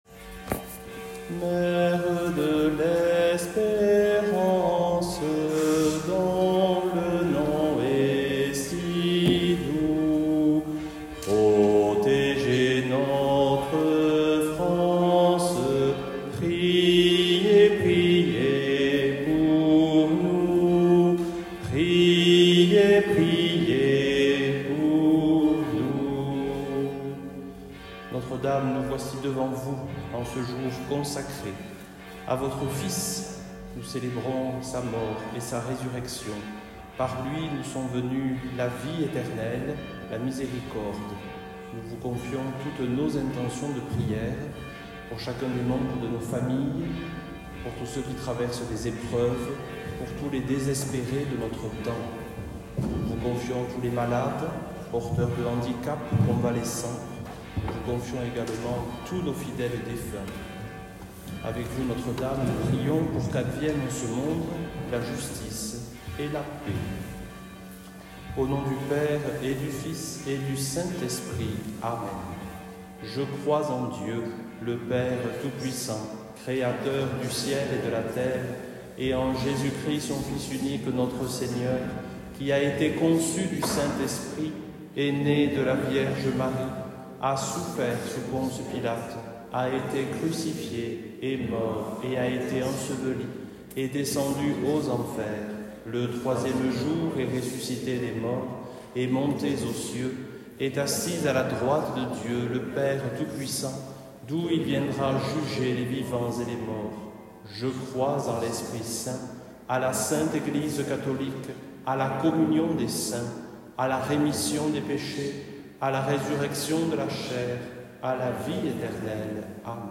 Chapelet dominical - Vox in deserto